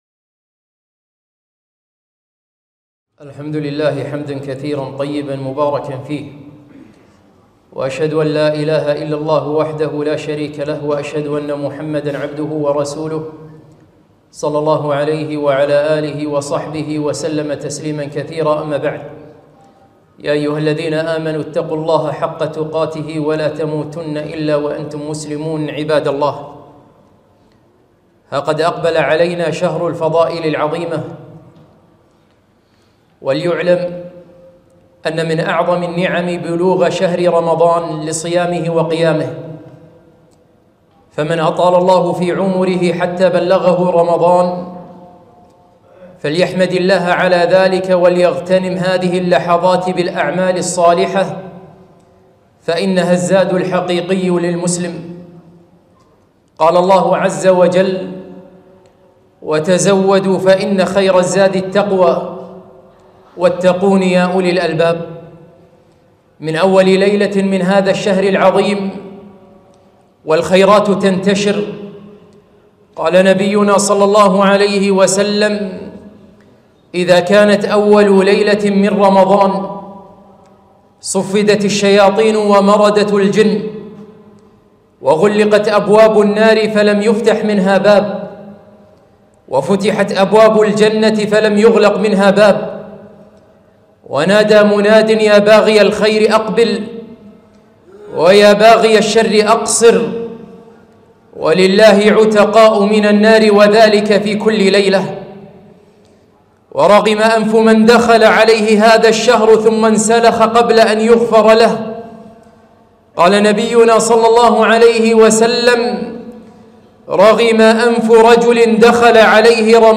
خطبة - ها قد أقبل رمضان